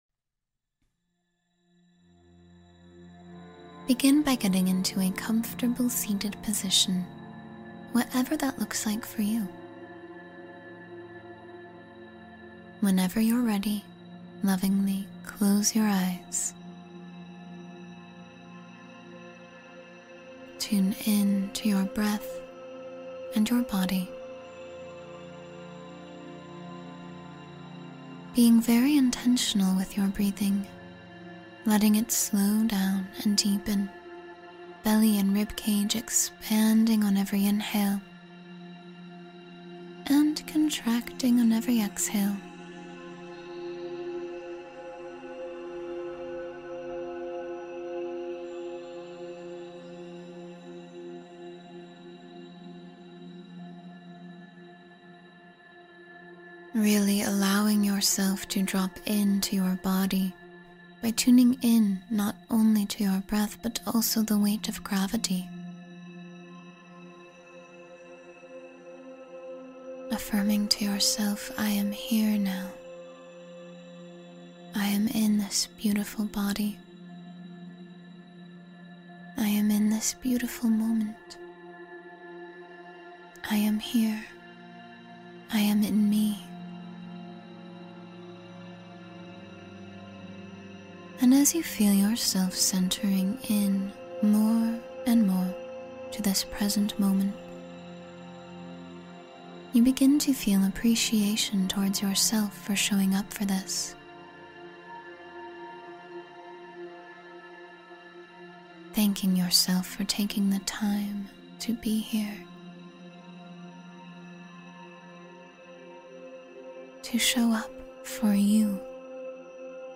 Let Go and Surrender to Peace — Meditation for Deep Release and Calm